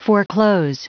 Prononciation du mot foreclose en anglais (fichier audio)
Prononciation du mot : foreclose